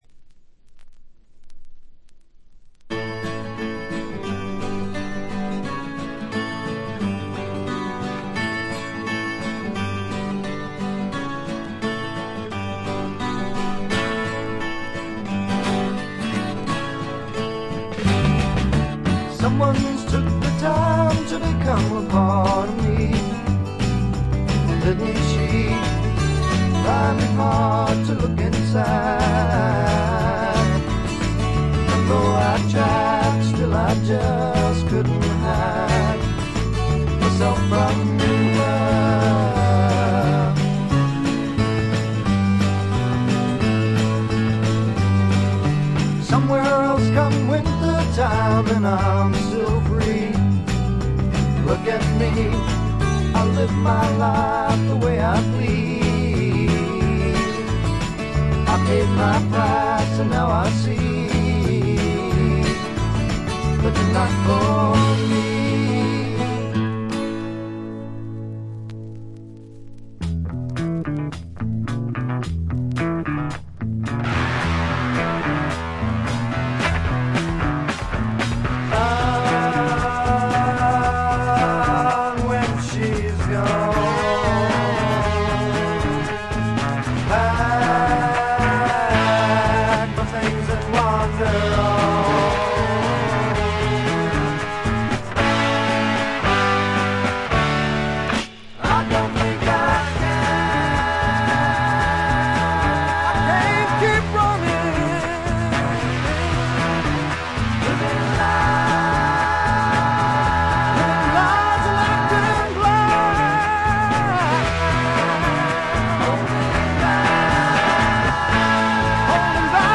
静音部でのバックグラウンドノイズや軽微なチリプチはありますが、気になるようなノイズはないと思います。
60年代的なサイケ要素がところどころで顔を出します。
試聴曲は現品からの取り込み音源です。
Recorded At - Record Plant, Los Angeles